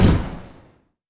etfx_explosion_mystic.wav